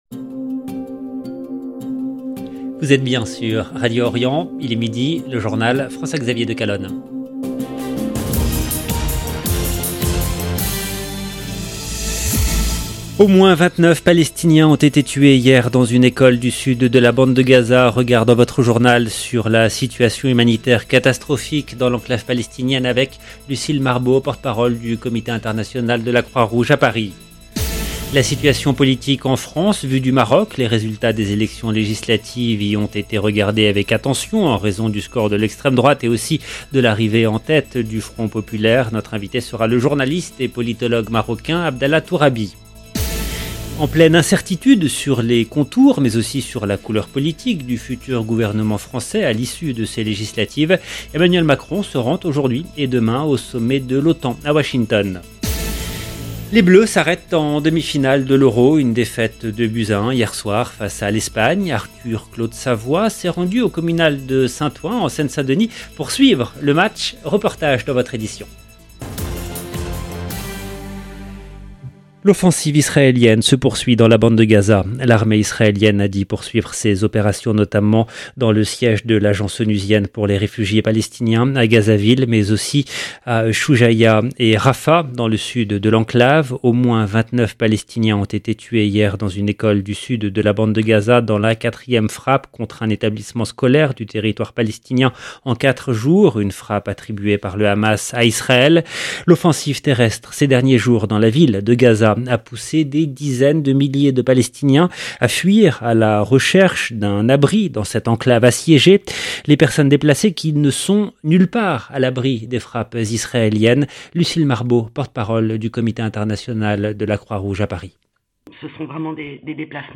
LE JOURNAL EN LANGUE FRANÇAISE DE MIDI DU 10/07/24